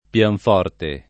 pianforte [ p L anf 0 rte ]